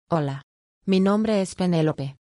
Female
Spanish - US